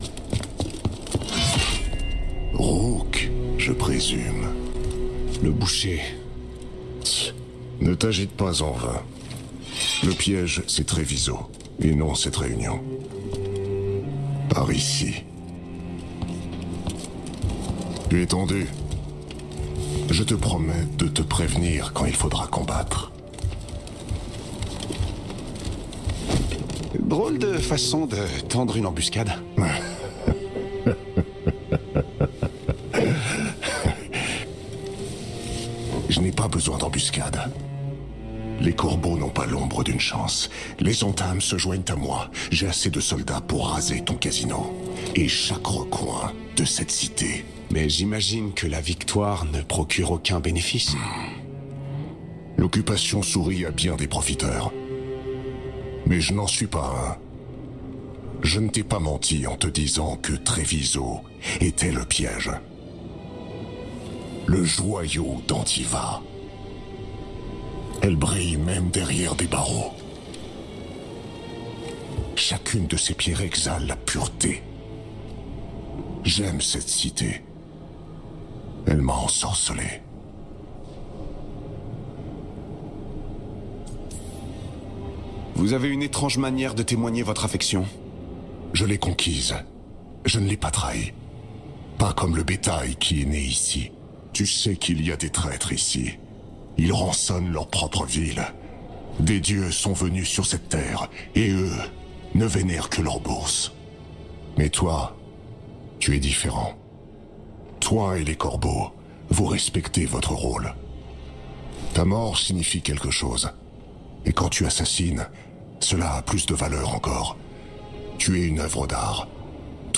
Doublage dans le jeu vidéo "Dragon Age IV" - Personnage du Boucher
Fou et torturé.
Rôle du Boucher.
Je suis allé chercher dans les graves de ma voix pour camper ce personnage à la stature imposante.
Mais au délà de ça, il était important de faire sentir la dangerosité et le déséquilibre mental de ce combattant d’un monde très sombre.